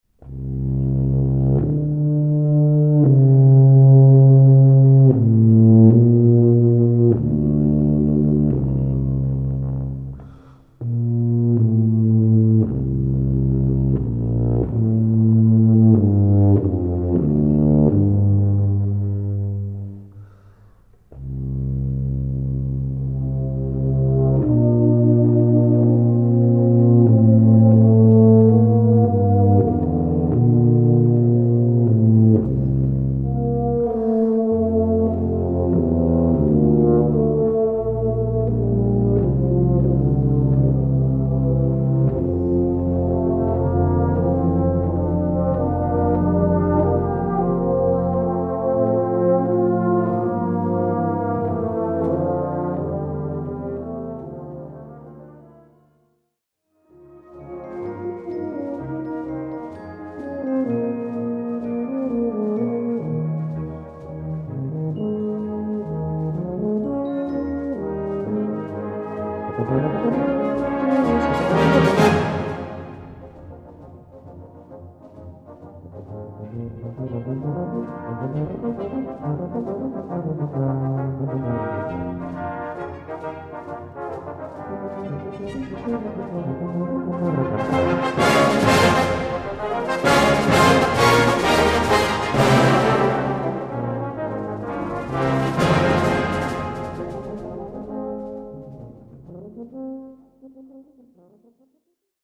Tuba et Brass Band